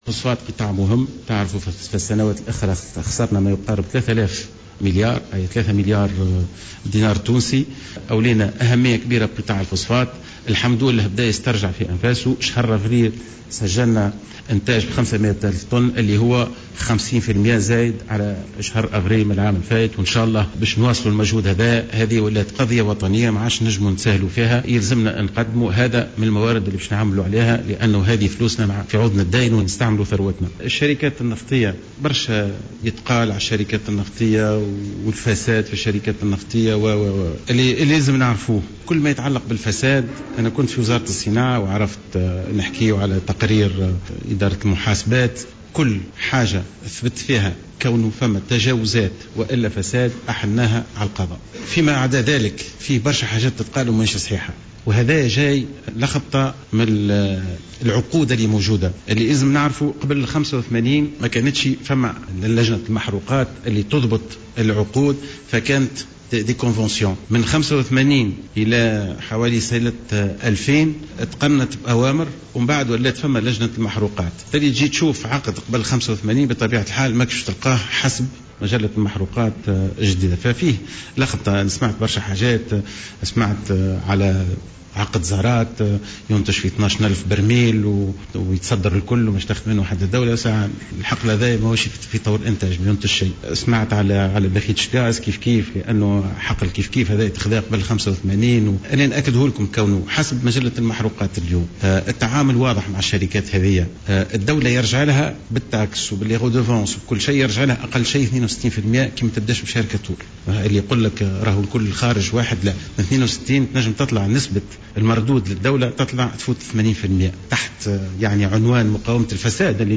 قال رئيس الحكومة المؤقتة مهدي جمعة خلال كلمة التي ألقاها اليوم خلال الندوة الصحفية بمناسبة مرور مائة يوم على تولي حكومته مهامها إن كثيرا من الأخبار الشائعة بخصوص الفساد في قطاع النفط غير صحيحة وخاصة على مستوى العقود المبرمة مع شركات البترول حيث تنظم لجنة المحروقات هذا القطاع منذ إحداثها سنة 1985.